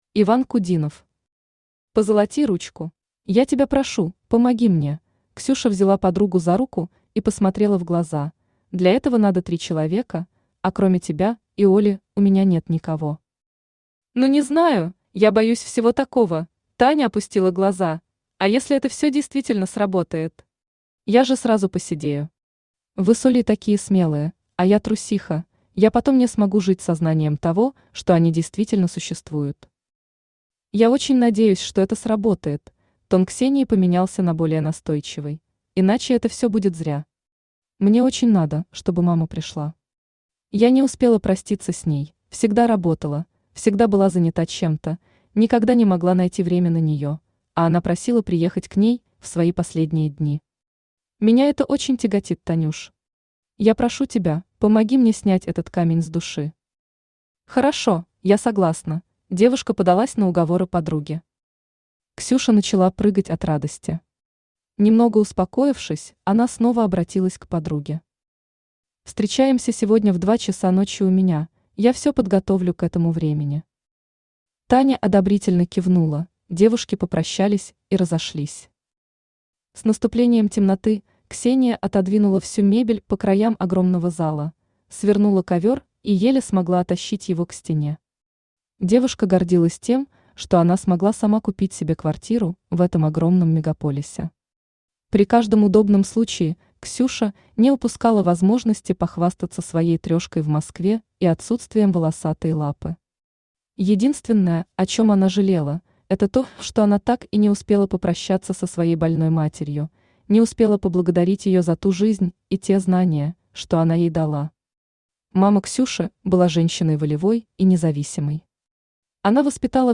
Aудиокнига Позолоти ручку Автор Иван Сергеевич Кудинов Читает аудиокнигу Авточтец ЛитРес.